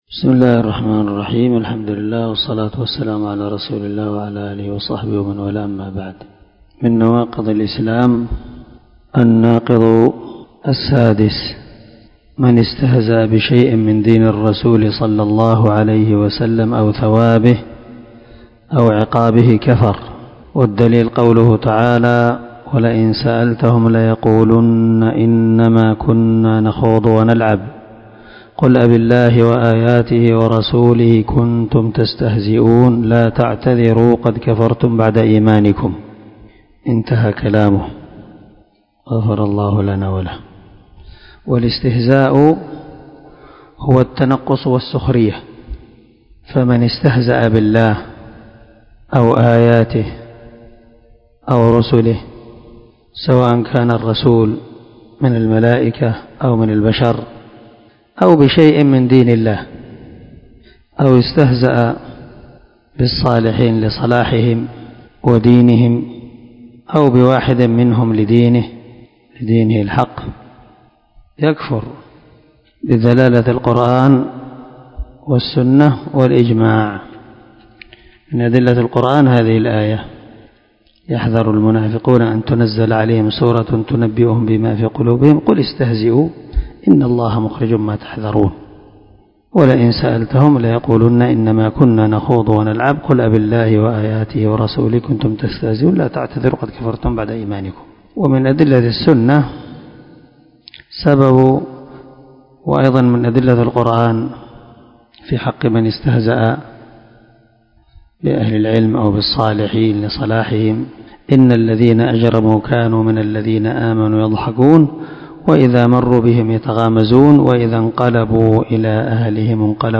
🔊الدرس 16 الناقض السادس ( من شرح الواجبات المتحتمات)